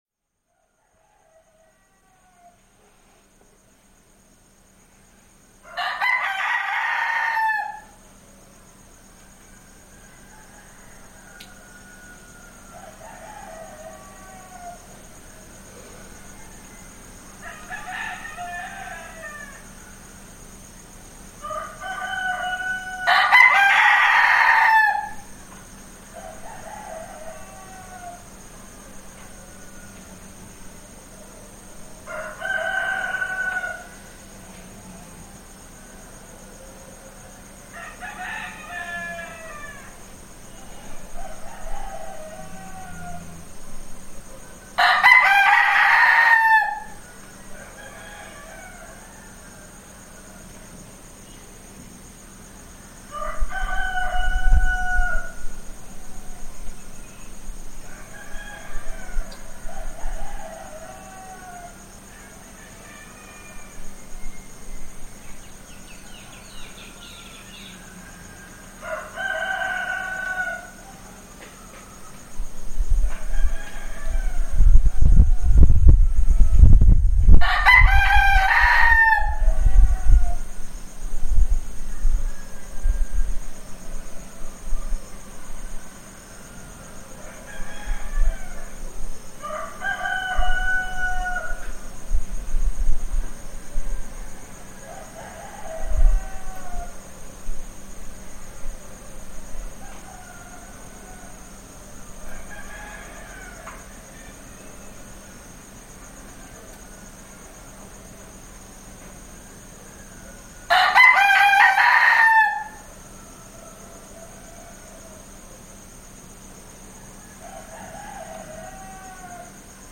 Gallos
Estamos en Azoyú, uno de los pueblos más hermosos de la Costa Chica de Guerrero, los gallos nos anuncian el nuevo día, es hora de despertar y de emprender una nueva aventura en esos días de vacaciones.
Equipo: Grabadora Sony ICD-UX80 Stereo